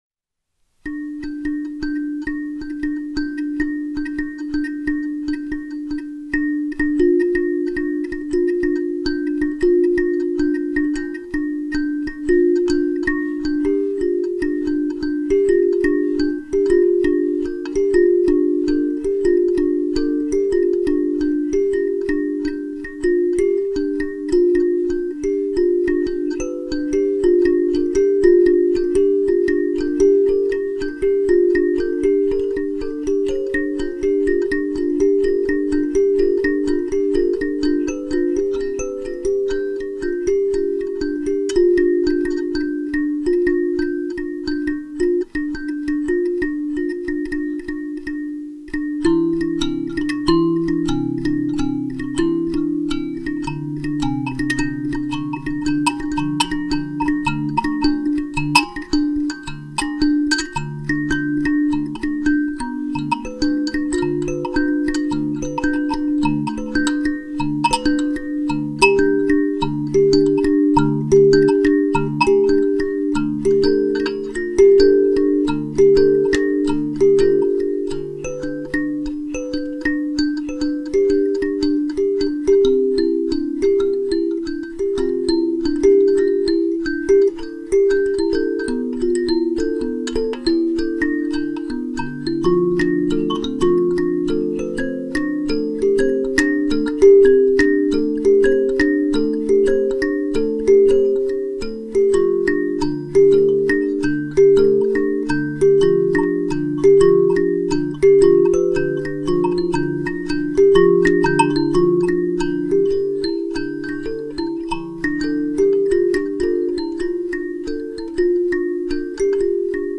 Practicing more on the kalimba today. I’m trying to play double notes.  This is an improvisation.
I played this in the den and recorded it using a Blue Snowflake USB mic connected to my new MacBook Air.
Kalimba
kalimba2.mp3